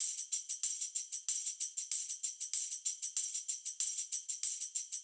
SSF_TambProc2_95-03.wav